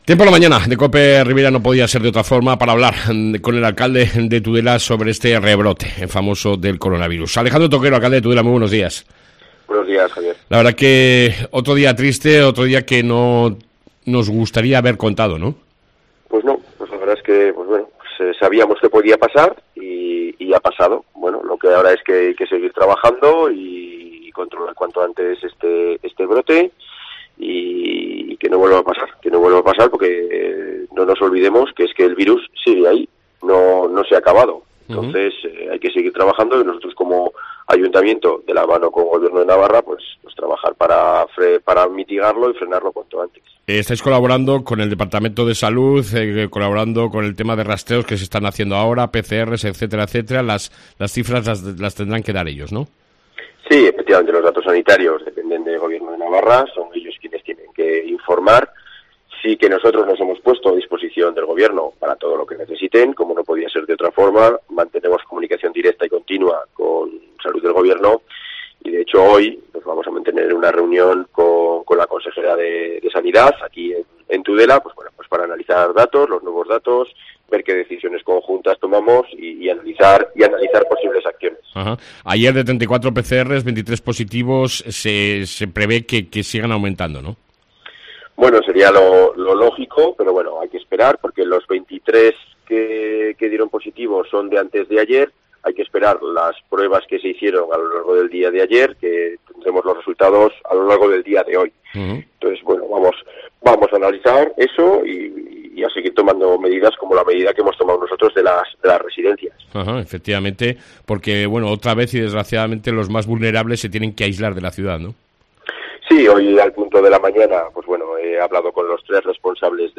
AUDIO: Alejandro Toquero, Alcalde de Tudela, habla de las medidas adoptadas tras el nuevo brote del COVID 19